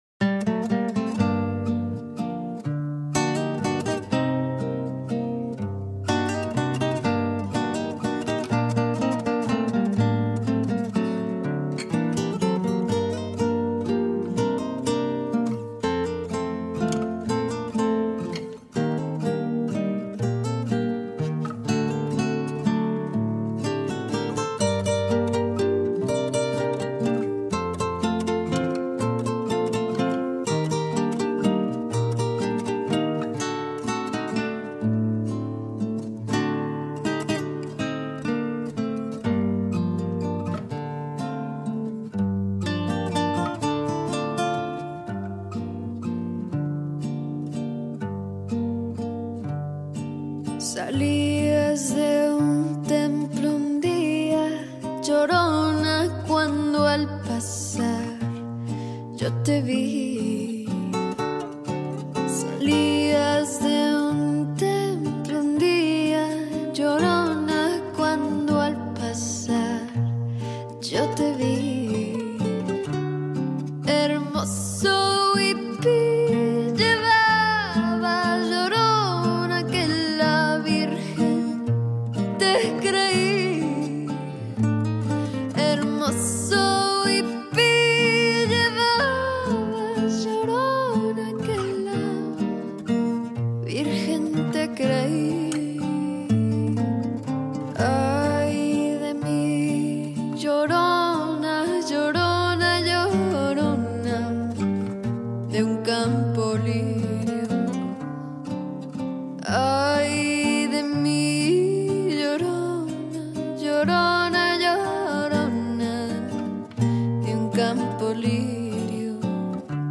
Cancion Española